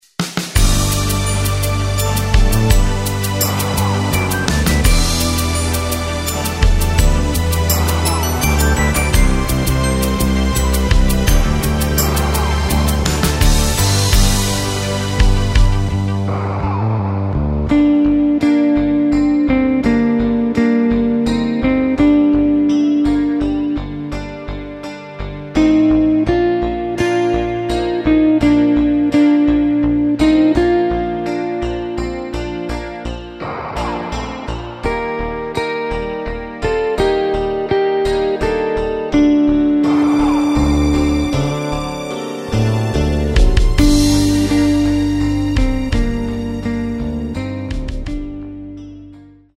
Takt:          3/4
Tempo:         84.00
Tonart:            G
Langsamer Walzer aus dem Jahr 1989!